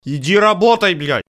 голосовые